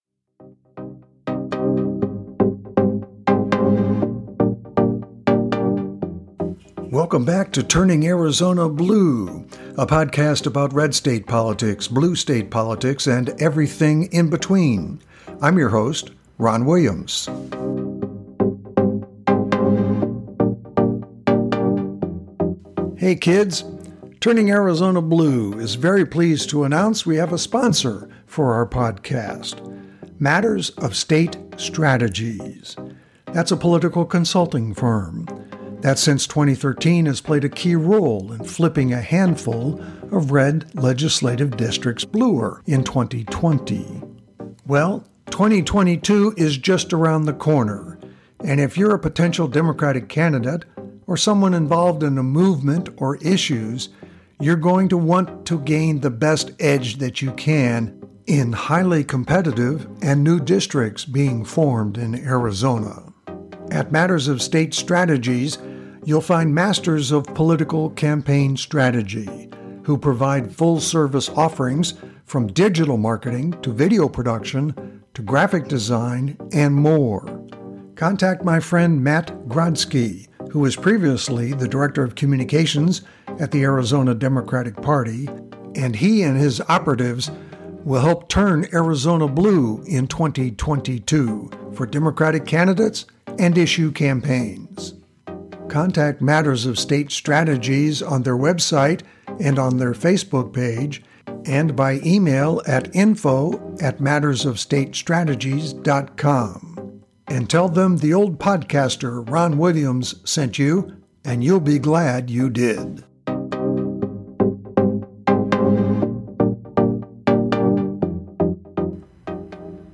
This episode caught the sounds of the “send-off” event